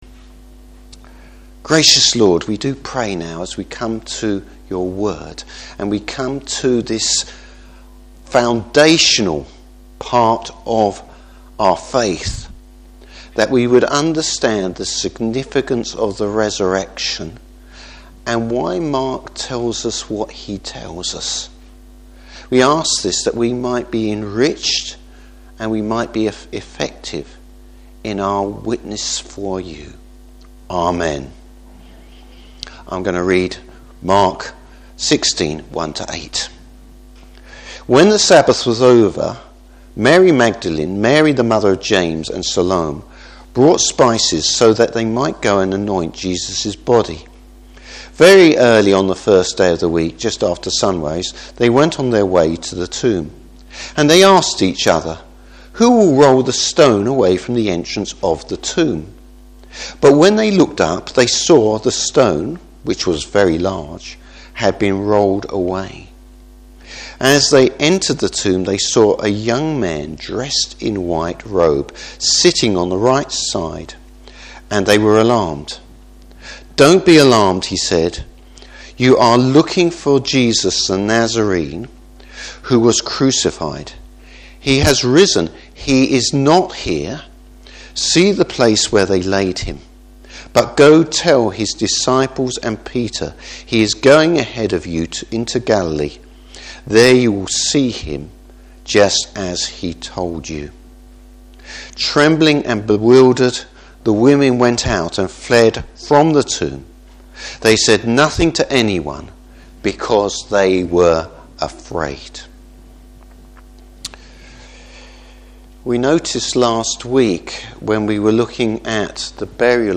Service Type: Morning Service What should be our response to Jesus’ resurrection?